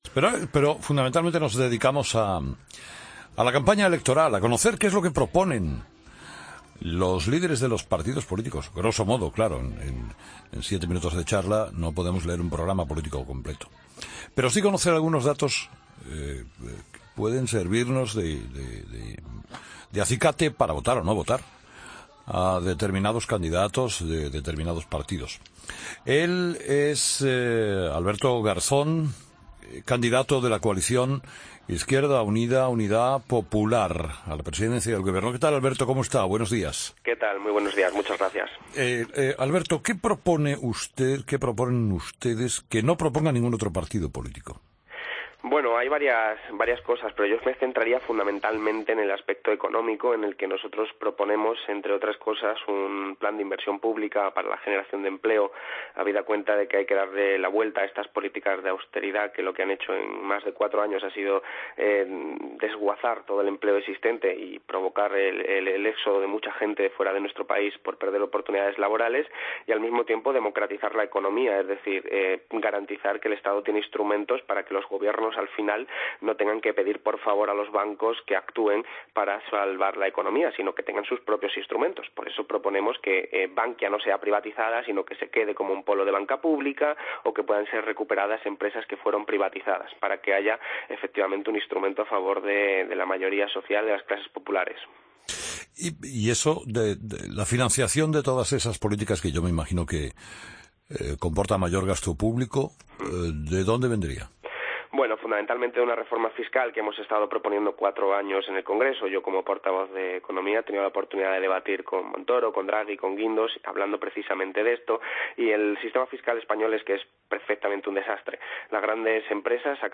Escucha la entrevista a Alberto Garzón en 'Herrera en COPE'